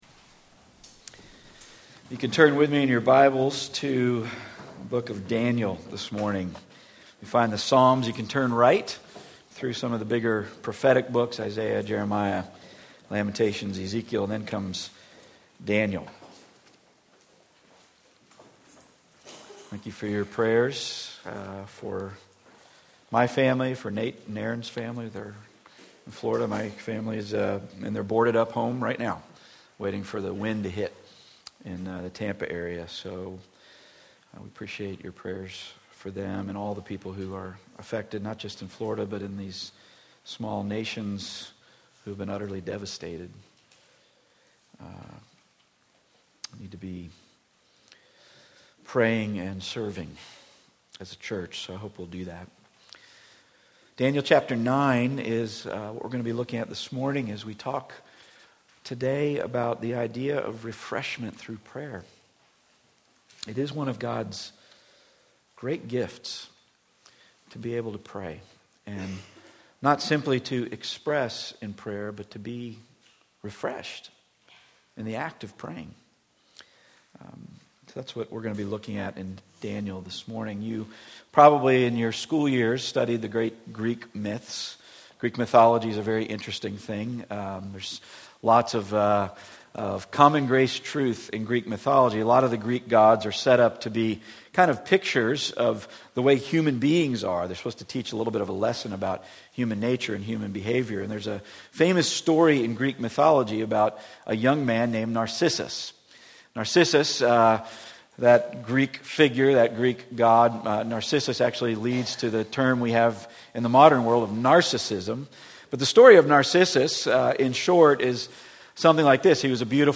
Passage: Daniel 9:1-23 Service Type: Weekly Sunday